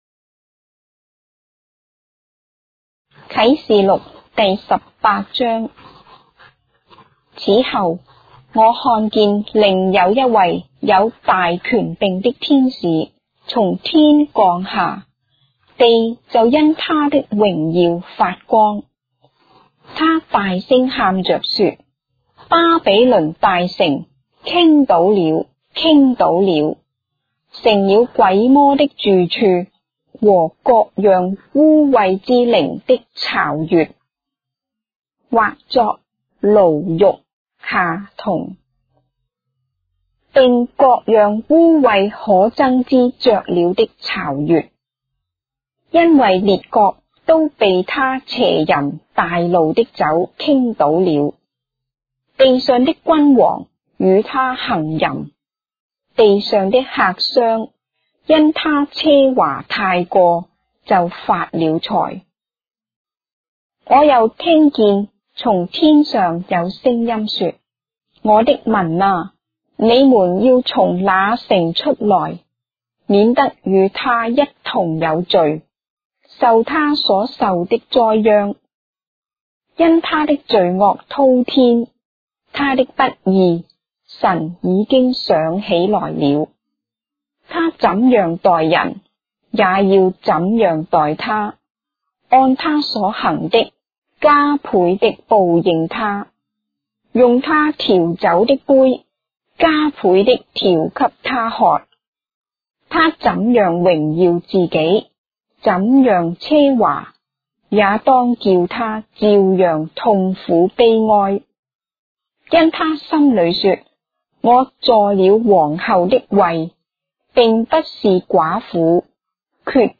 章的聖經在中國的語言，音頻旁白- Revelation, chapter 18 of the Holy Bible in Traditional Chinese